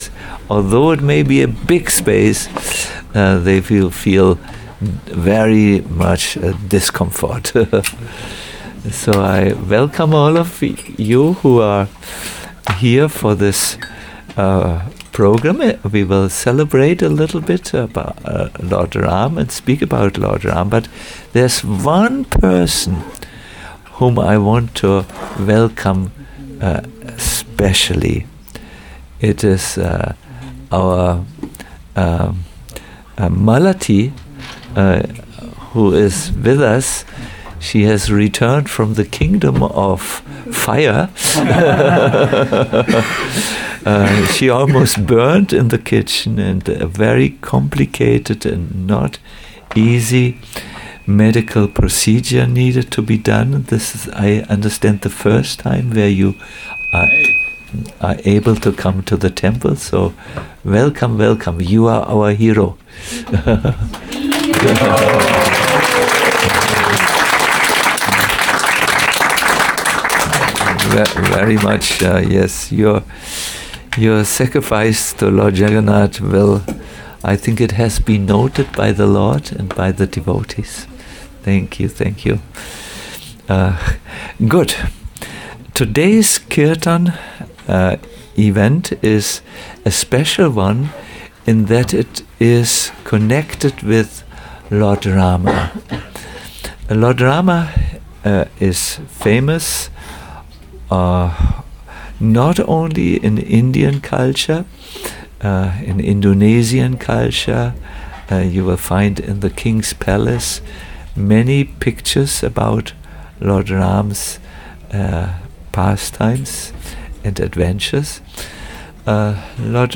a lecture
Berlin